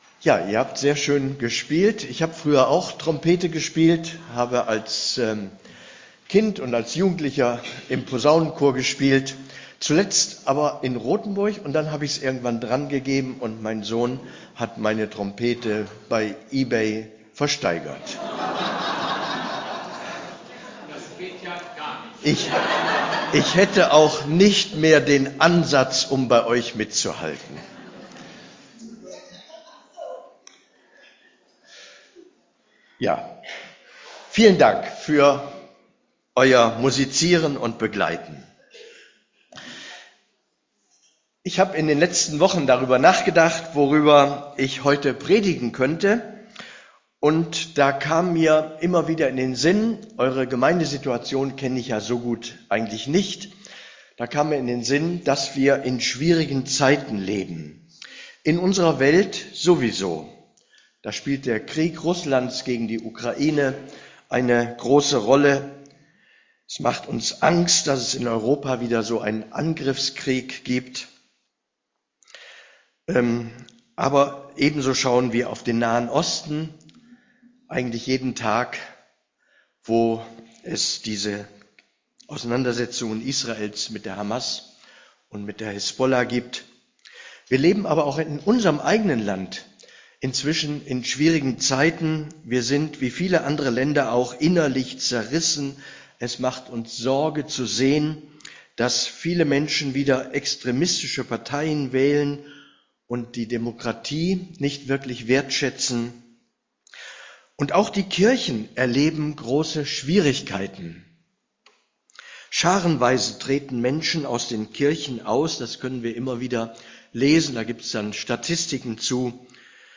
(Festgottesdienst)